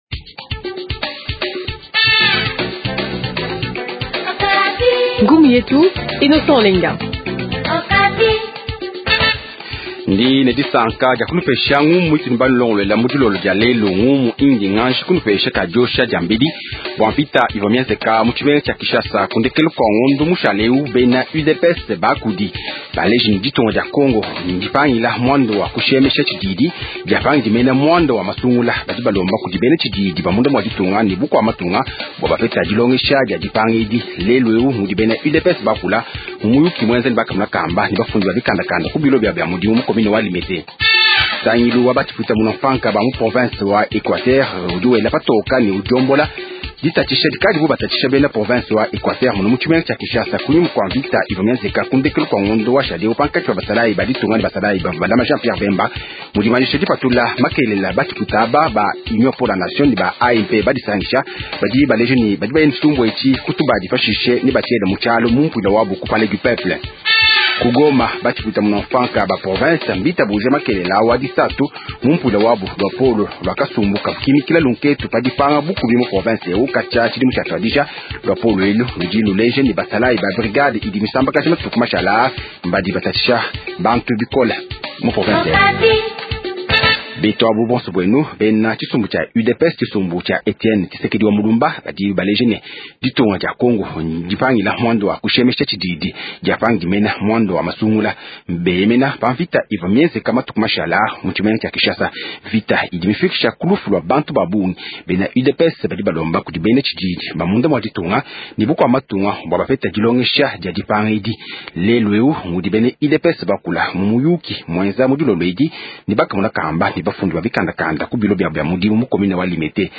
Journal Tshiluba Soir